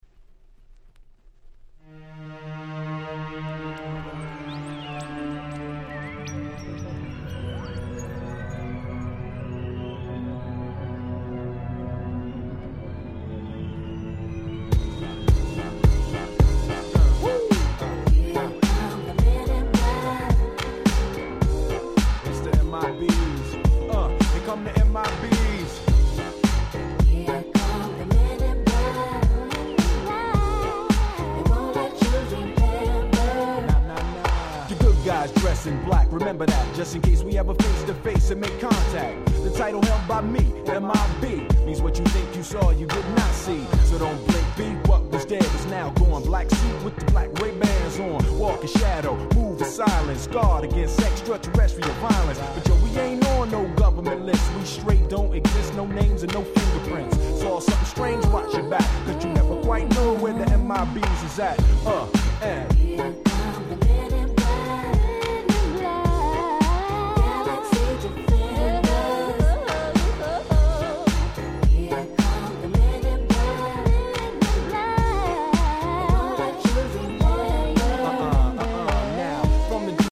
97' Super Hit Hip Hop LP !!